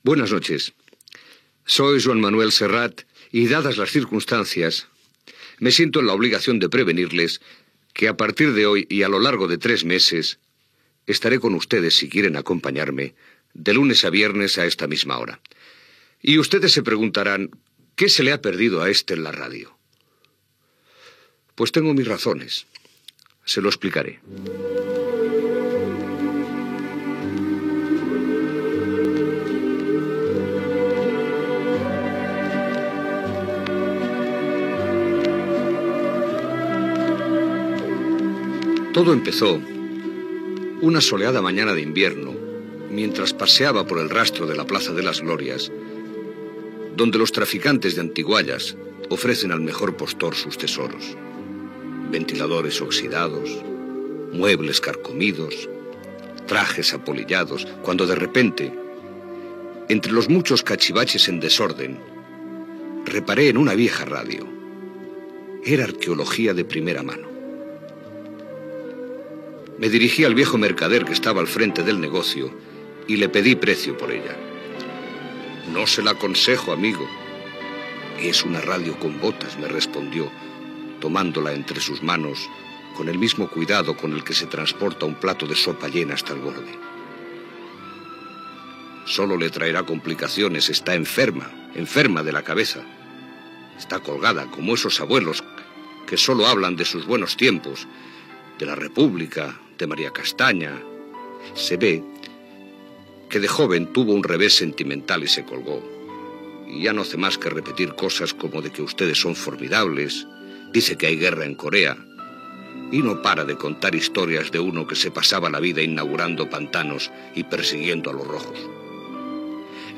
aab20c3eee003c2c6ec28dce556d74c6447d1782.mp3 Títol Radio 5 Emissora Radio 5 Barcelona Cadena RNE Titularitat Pública estatal Nom programa La radio con botas Descripció Salutació del primer episodi de la sèrie dedicat a l'any 1940 i careta. Enregistrament radiofònic de la Guerra Civil, "No pasarán"